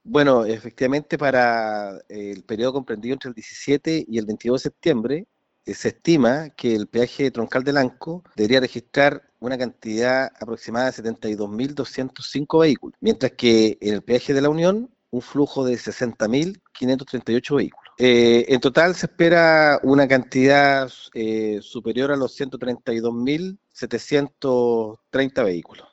Si bien desde el Gobierno indicaron previamente que serían más de 200 mil vehículos que ingresarían a la región de Los Ríos, finalmente el delegado Presidencial, Jorge Alvial, corrigió dicha cifra y precisó que serán 130 mil, detallando que se proyecta la circulación de 72 mil vehículos por el peaje de Lanco y 60 mil por el de La Unión entre el 17 y 22 de septiembre.